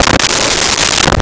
Glass Slide Only 02
Glass Slide Only 02.wav